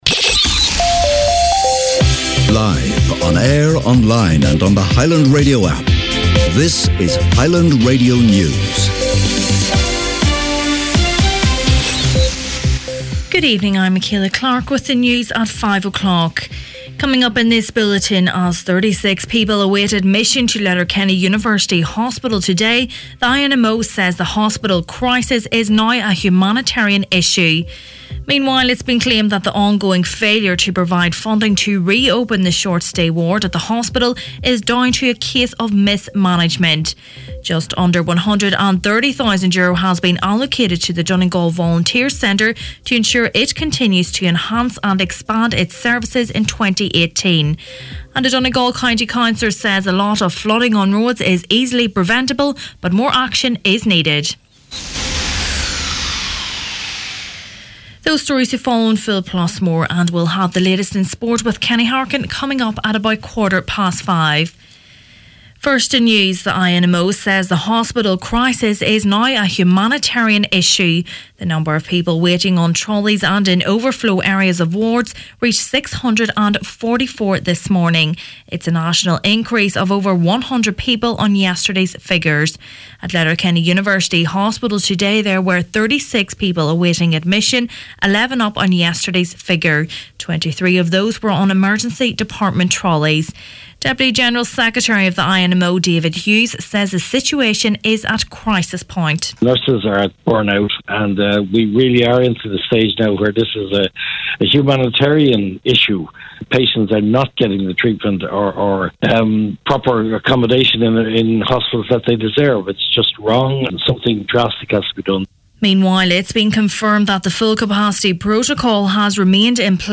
Main Evening News, Sport and Obituaries Tuesday 30th January